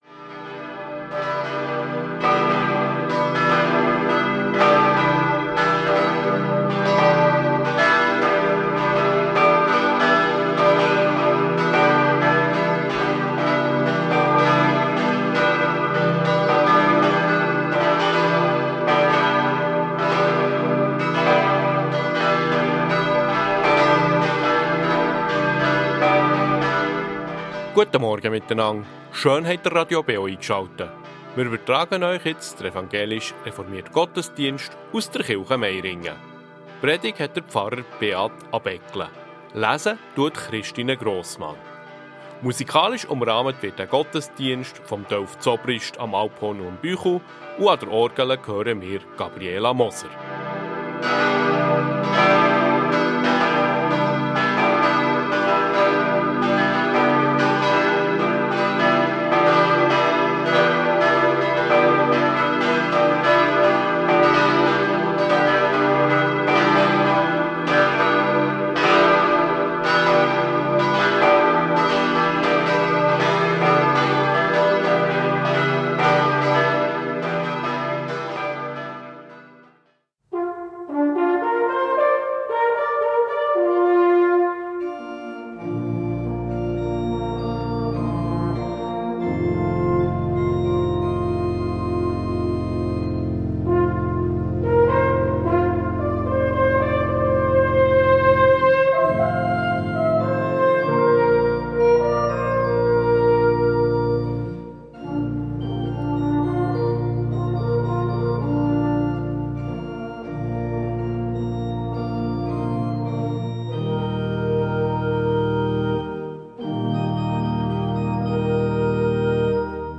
Reformierte Michaelskirche Meiringen ~ Gottesdienst auf Radio BeO Podcast